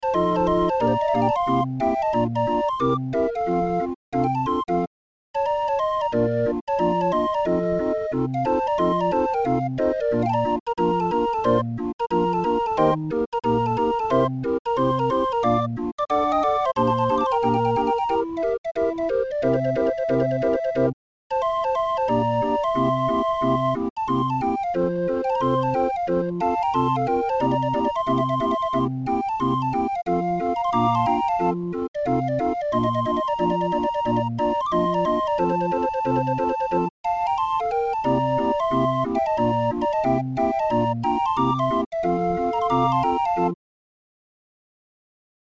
Muziekrol voor Raffin 31-er